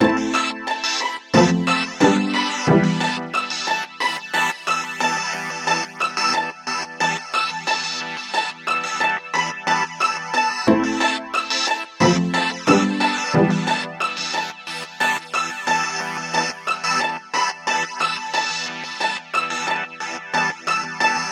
标签： 90 bpm RnB Loops Strings Loops 3.59 MB wav Key : Unknown
声道立体声